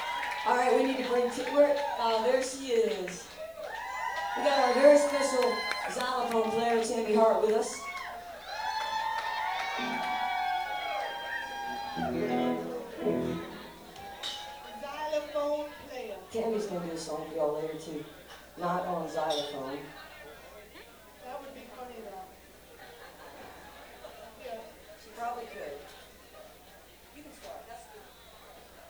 (band show)
10. talking with the crowd (0:28)